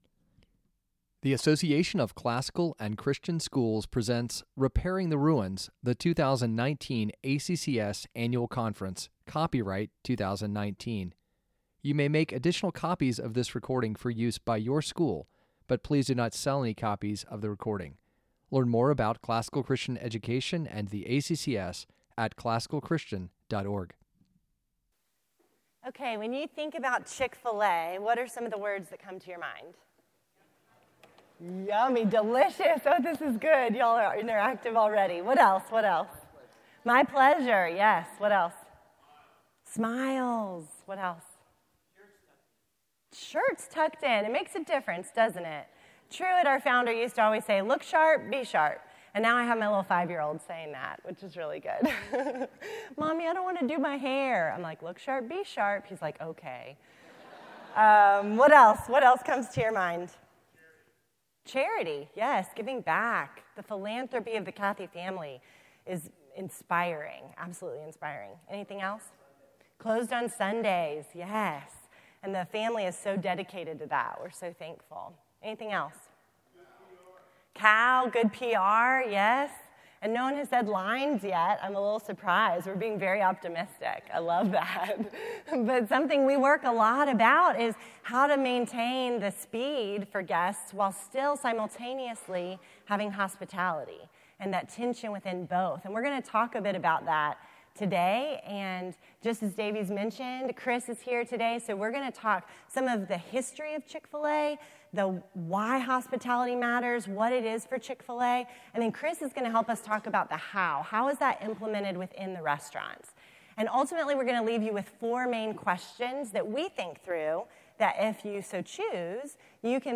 2019 Leaders Day Talk | 46:03 | Leadership & Strategic, Marketing & Growth, Operations & Facilities
Additional Materials The Association of Classical & Christian Schools presents Repairing the Ruins, the ACCS annual conference, copyright ACCS.